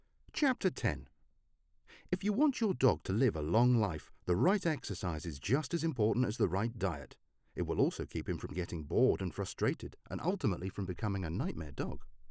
weird modulation(?) on audio recording - help!
Audacity 2.3.3 OS X El Capitan 10.11.6 Rode NT1-A Scarlett Solo Hello, everybody.
I’ve just started audiobook recording.
I’m using a brand new (as in arrived today) Scarlett Solo/Rode NT1-A set up into my MacBook Air and I’m seeing and hearing a weird crackle on some of the recorded audio.